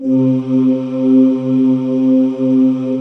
Index of /90_sSampleCDs/USB Soundscan vol.28 - Choir Acoustic & Synth [AKAI] 1CD/Partition C/09-GREGOIRE